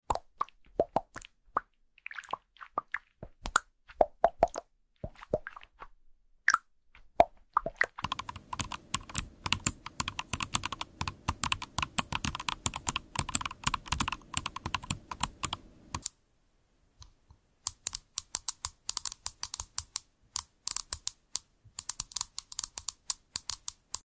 Typing on a Keyboard That sound effects free download